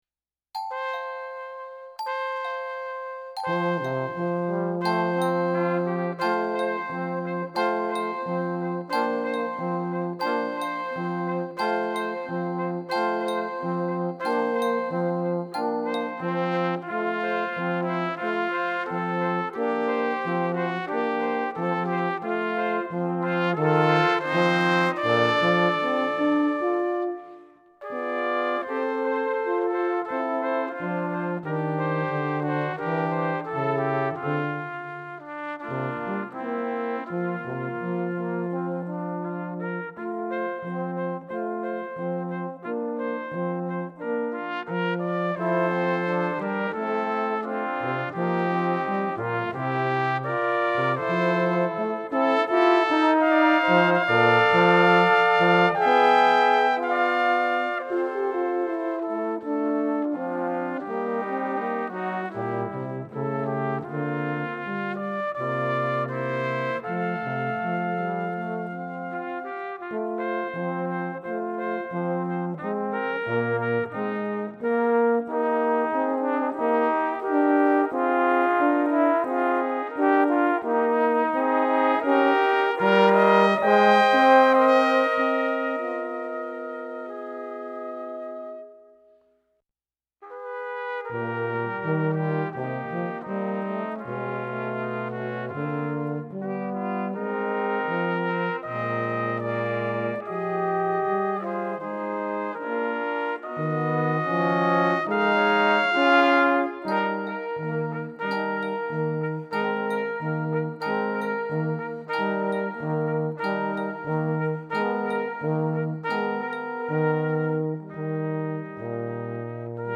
Voicing: Brass Quartet